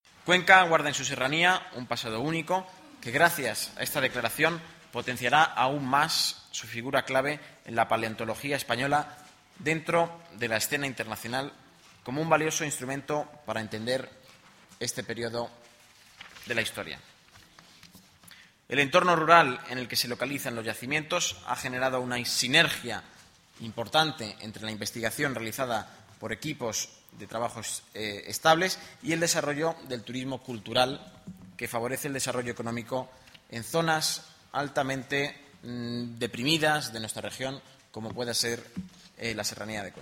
El portavoz del Gobierno regional, Nacho Hernando, declaración BIC Las Hoyas